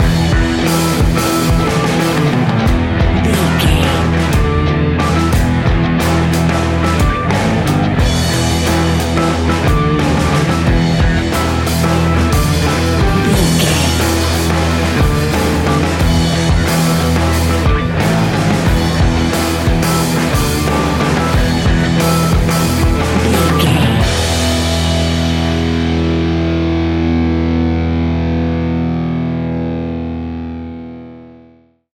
Ionian/Major
guitars
hard rock
heavy rock
distortion
instrumentals